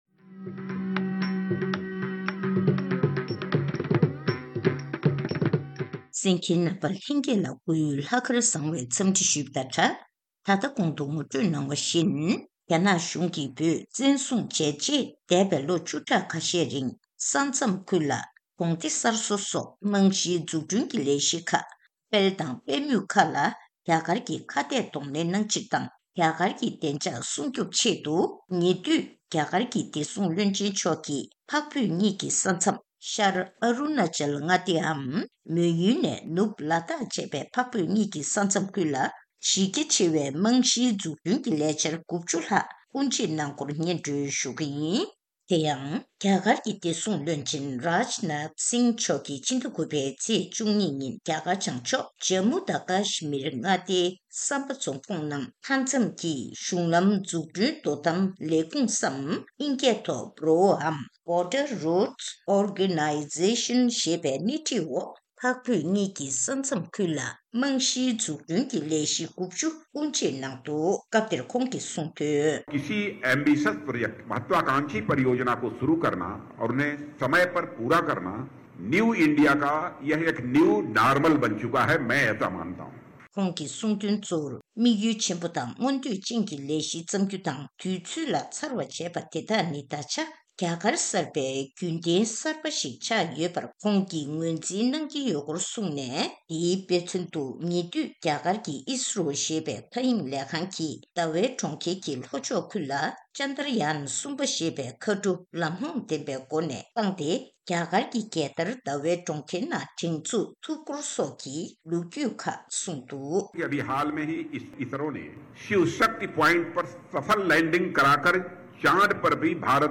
གནས་འདྲི་ཞུས་ནས་གནས་ཚུལ་ཕྱོགས་བསྒྲིགས་ཞུས་པ་ཞིག་གསན་རོགས་གནང་།